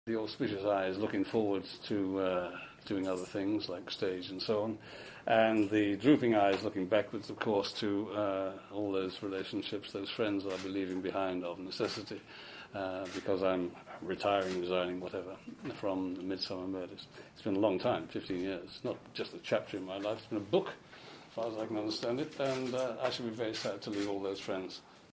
But first here are a few short audio extracts from John’s final Midsomer press conference: